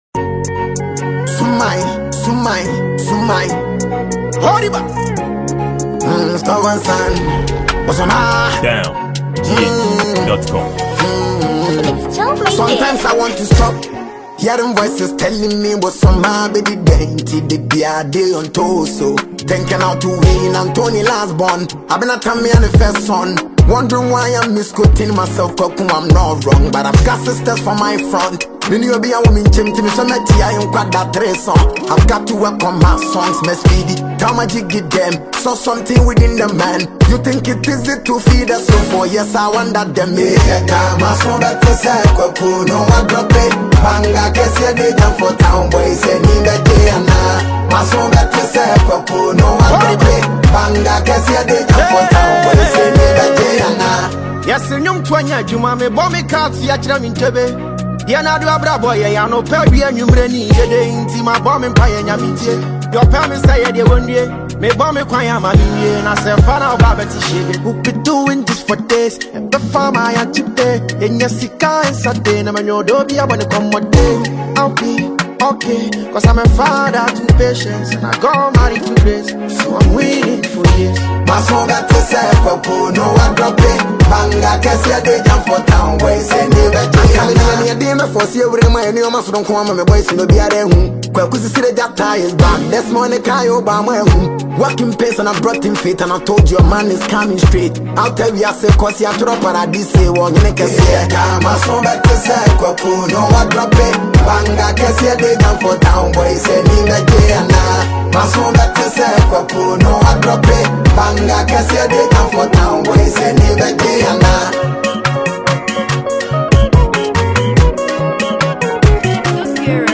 Ghanaian very-skilled hiphop drill trapper